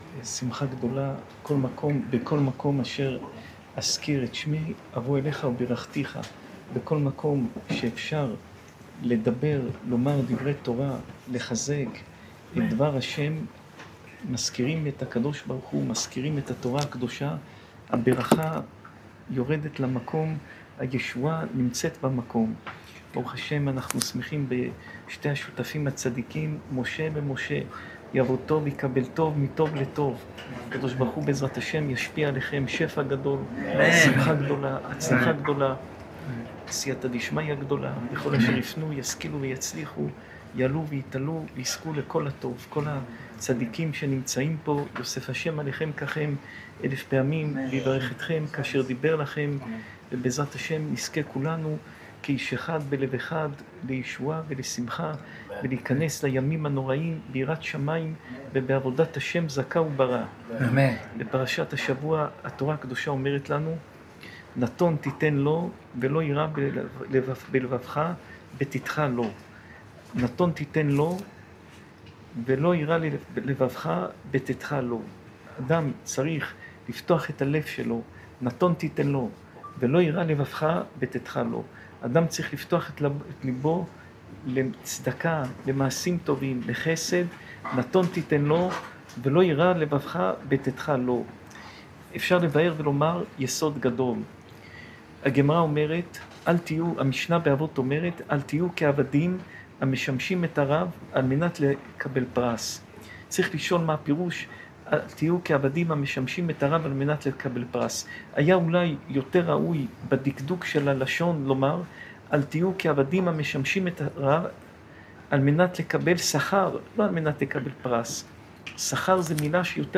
שעור תורה